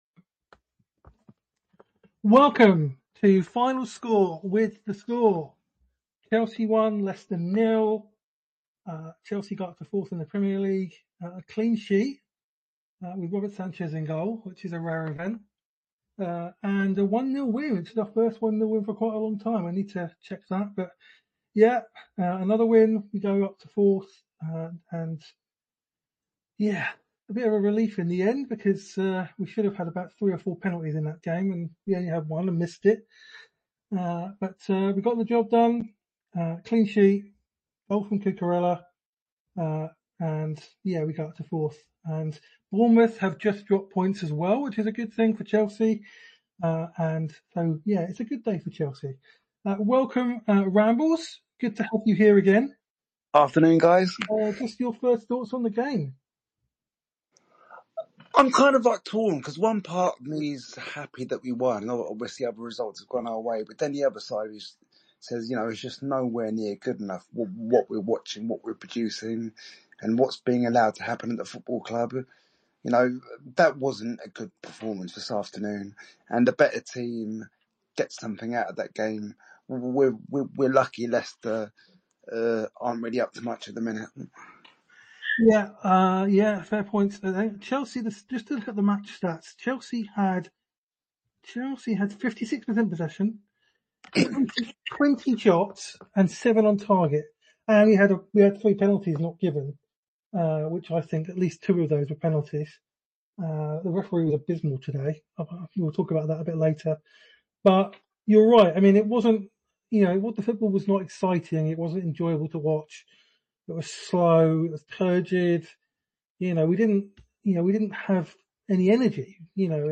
takes your calls